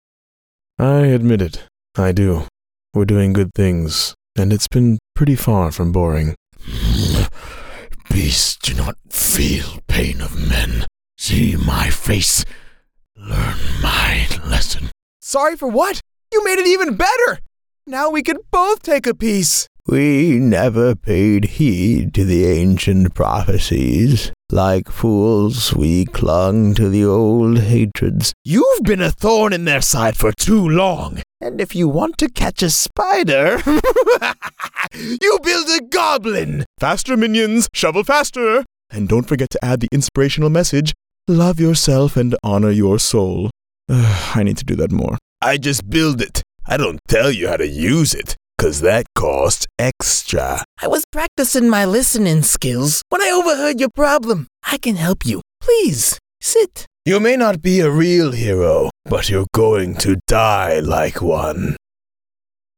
Animation , Characters , Male , Specialty Voices , Versatile